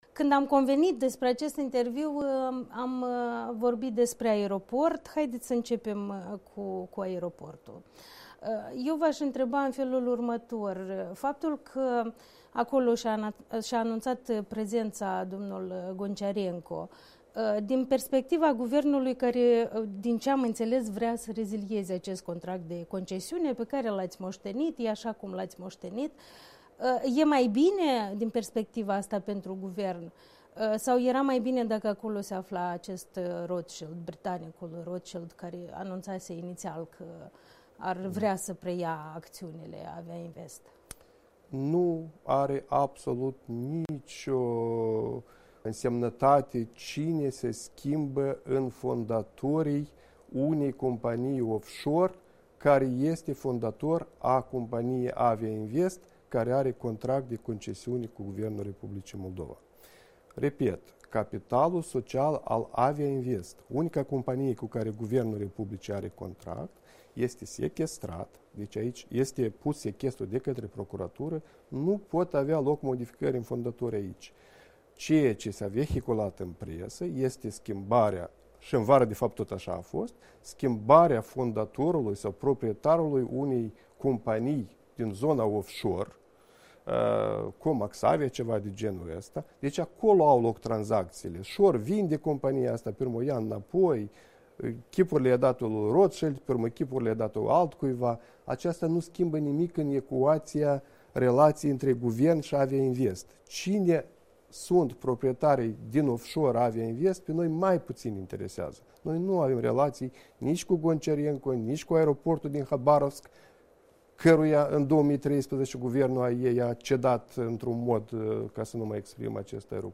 Interviu cu primul ministru Ion Chicu